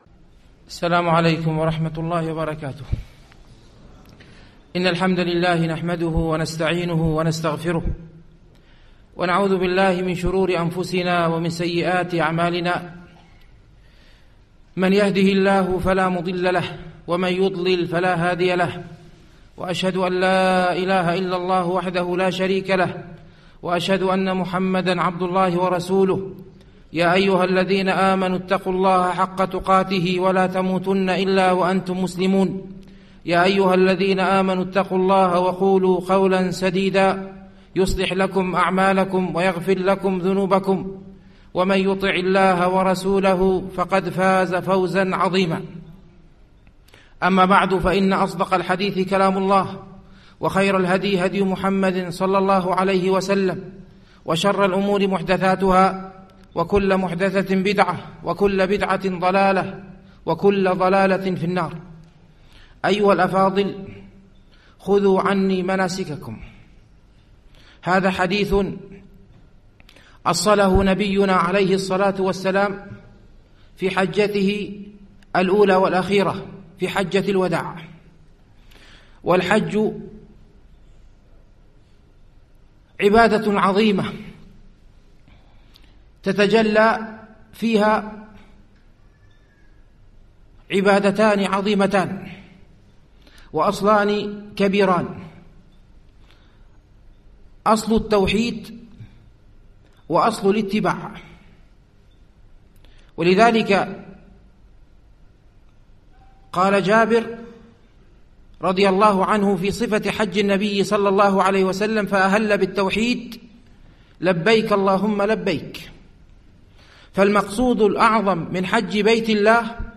الدرس 4